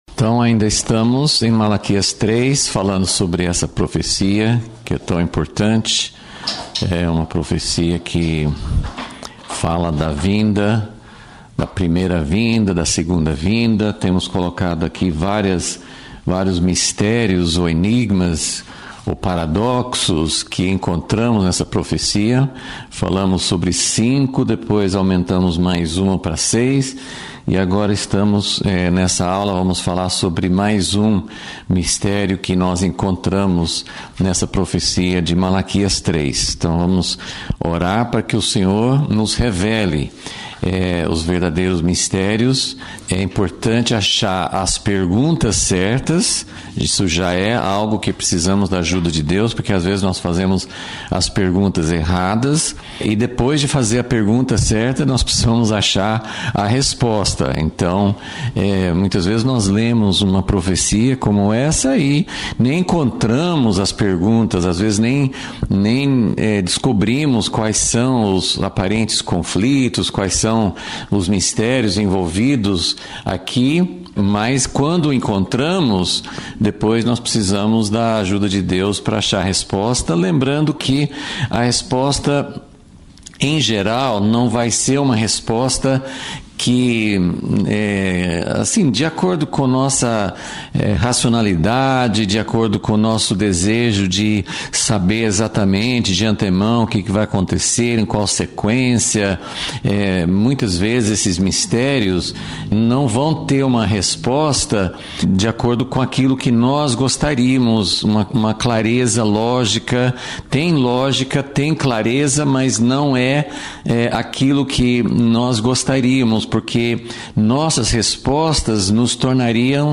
Aula 15 – Vol. 37 – O Mistério da Vinda Antes da Segunda Vinda